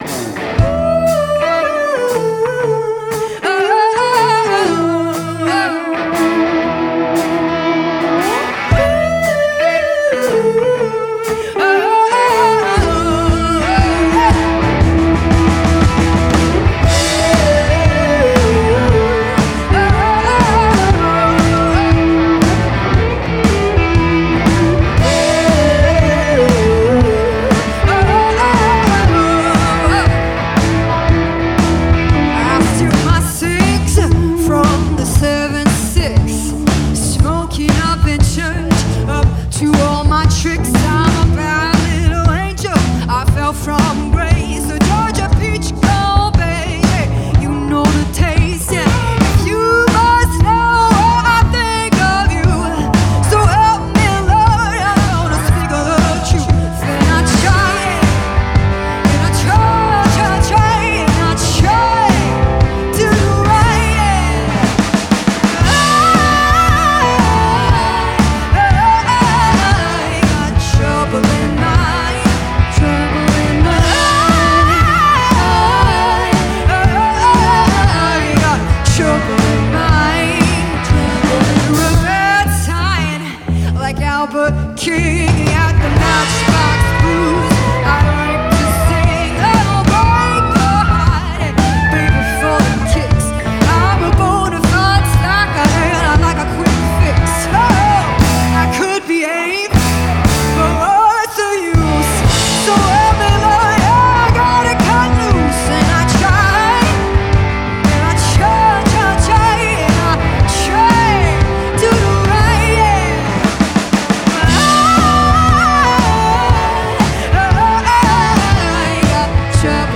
We believe in presenting artists exactly as they perform.